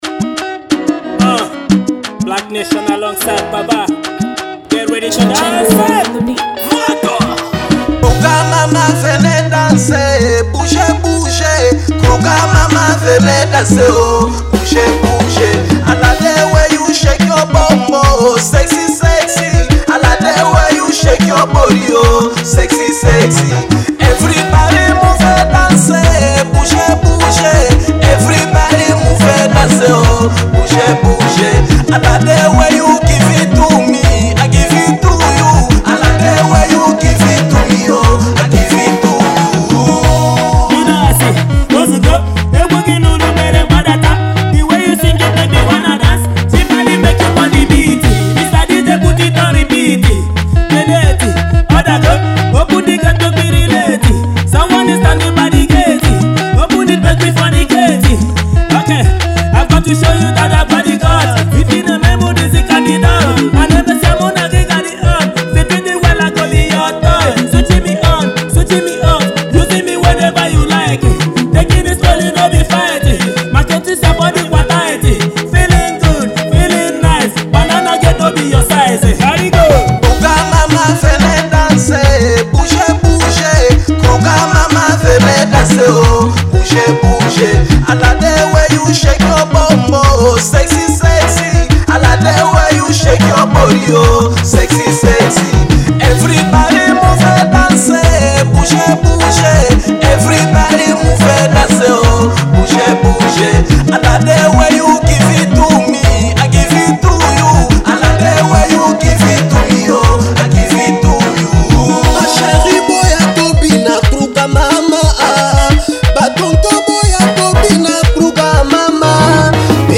Alternative Rap